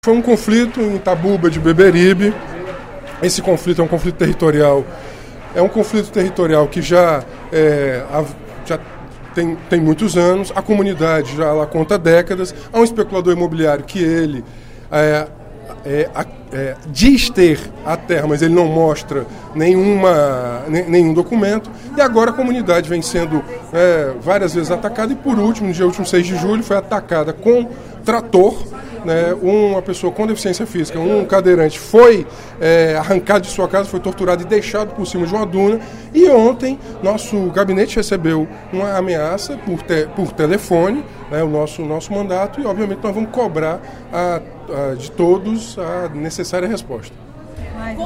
O deputado Renato Roseno (Psol) denunciou, nesta terça-feira (13/07), no primeiro expediente, que a especulação imobiliária está colocando em risco comunidades tradicionais no Estado. O parlamentar criticou a maneira violenta, segundo ele, com que teria sido tratada a comunidade Tabuba, localizada no município de Beberibe, por um especulador imobiliário.